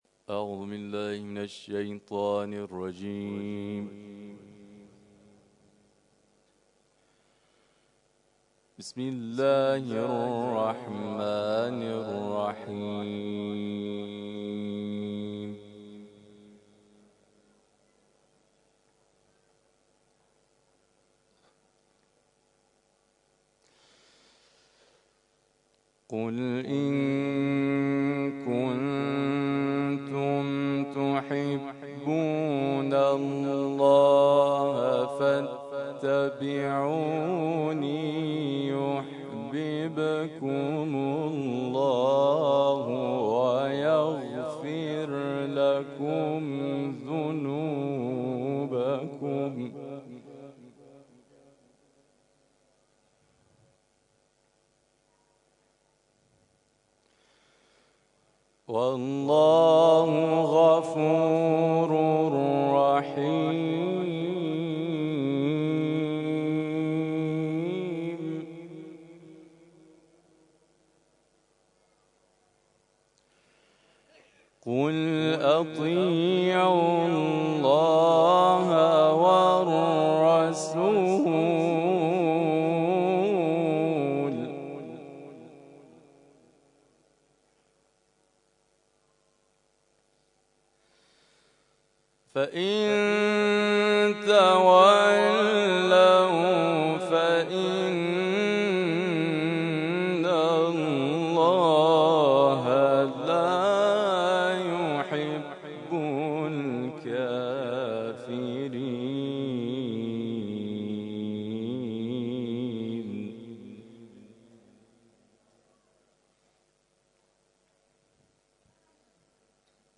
تلاوت
صدو چهل و یکمین کرسی تلاوت نفحات‌القرآن شب گذشته برگزار شد